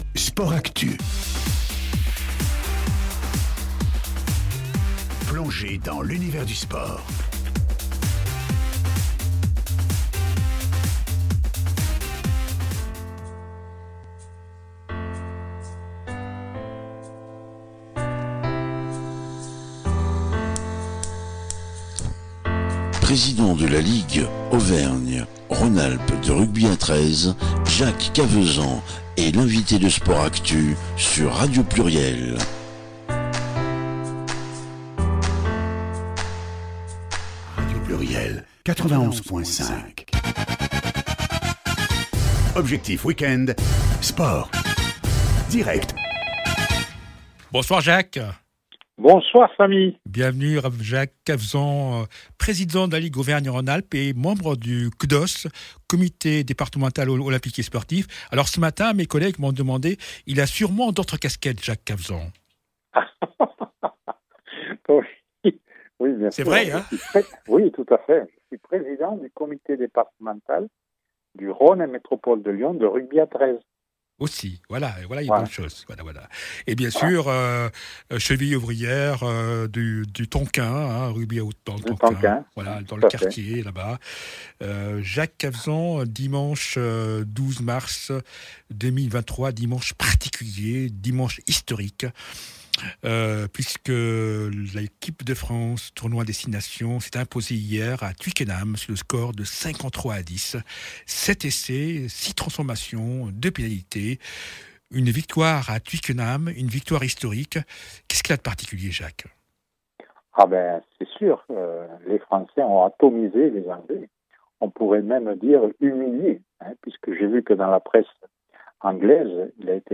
L’interview du président mois de MARS 2026